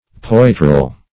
Search Result for " poitrel" : The Collaborative International Dictionary of English v.0.48: Poitrel \Poi"trel\, n. [OE. poitrel, F. poitrail, fr. L. pectorale a breastplate, fr. pectoralis, a. See Pectoral , a.]